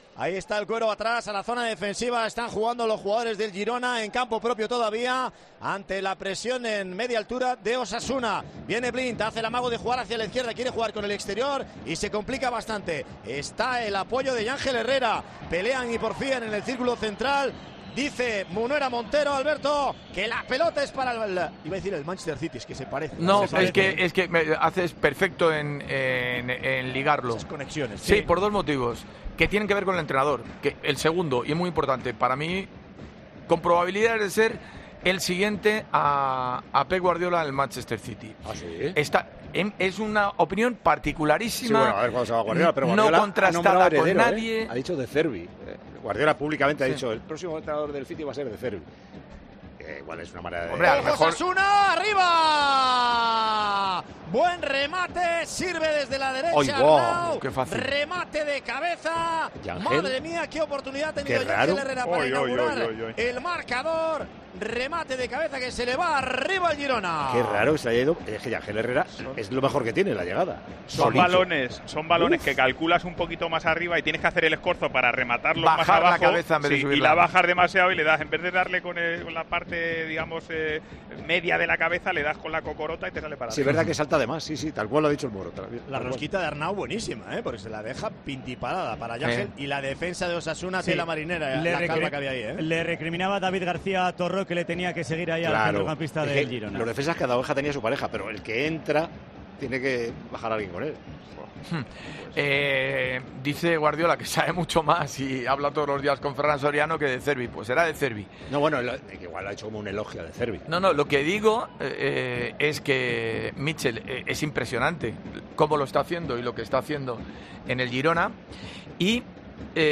El comentarista de Tiempo de Juego da su favorito para suceder a Pep Guardiola, para cuando el catalán se marche del Manchester City.